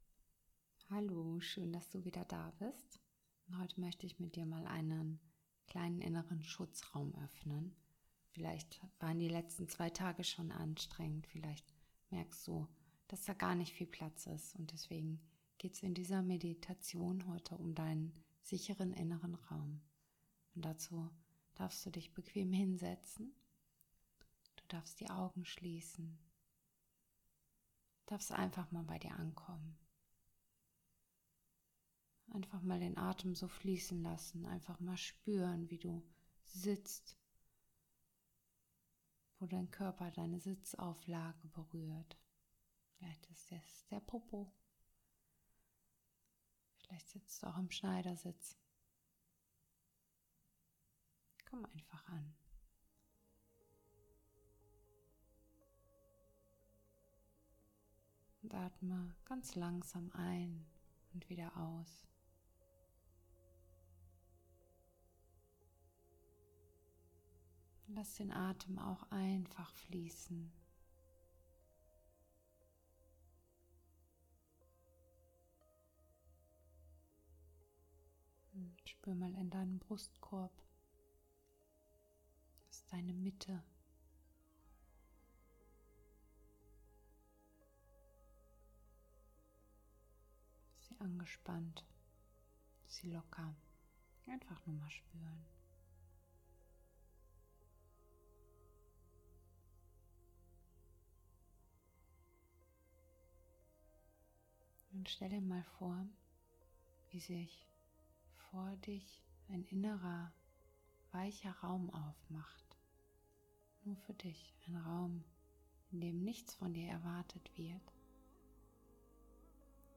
Mit ein paar ruhigen Atemzügen, die dich zurück in deinen